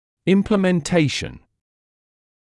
[ˌɪmplɪmen’teɪʃn][ˌимплимэн’тэйшн]выполнение, осуществление